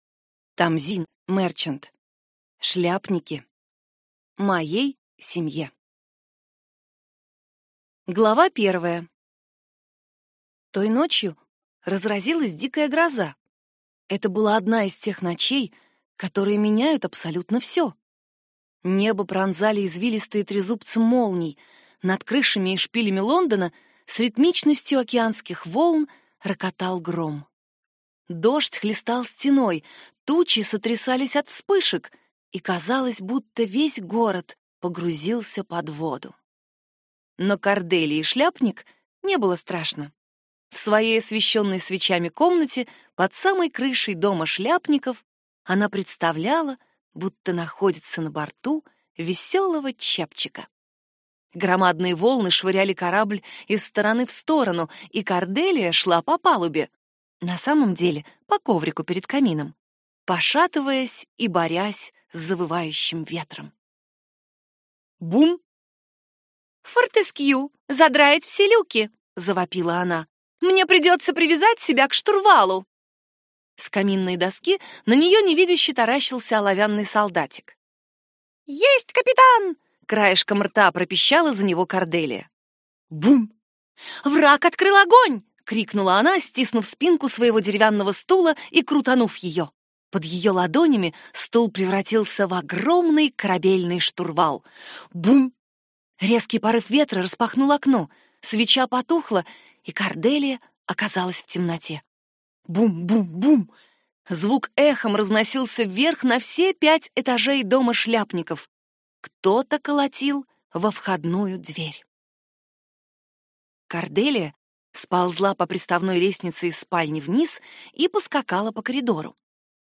Аудиокнига Шляпники | Библиотека аудиокниг
Прослушать и бесплатно скачать фрагмент аудиокниги